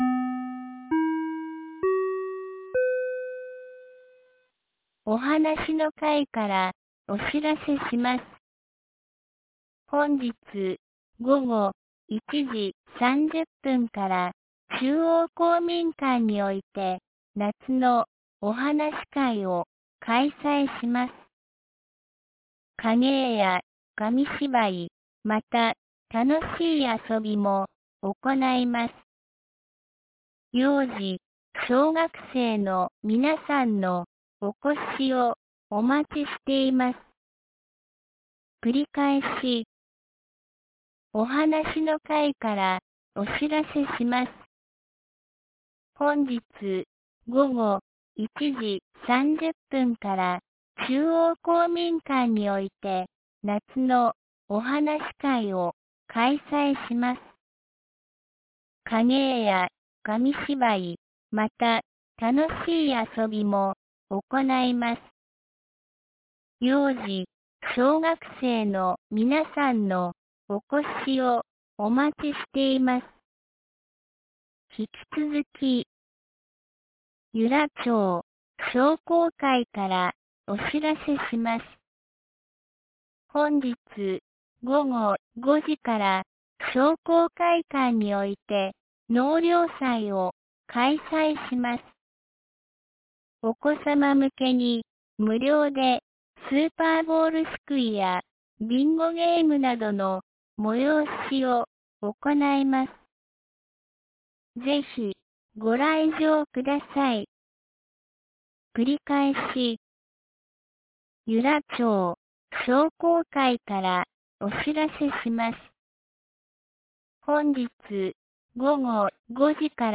2019年08月03日 12時22分に、由良町より全地区へ放送がありました。
放送音声